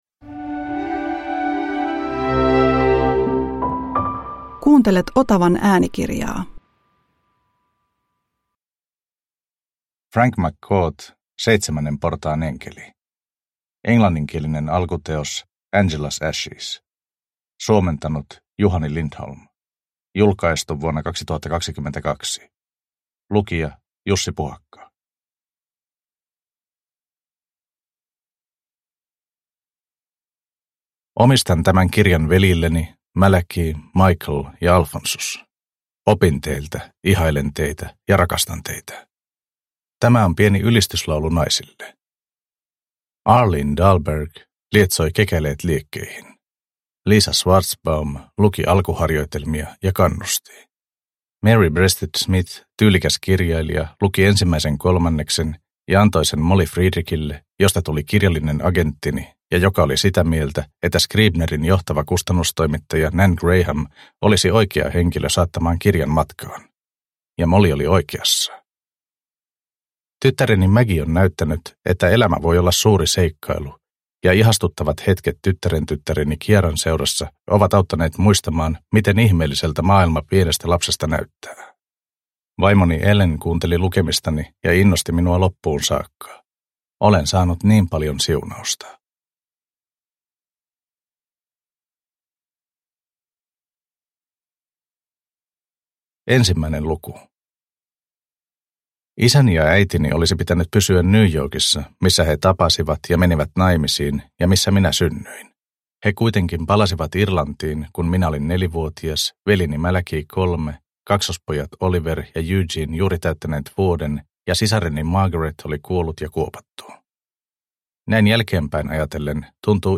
Seitsemännen portaan enkeli – Ljudbok – Laddas ner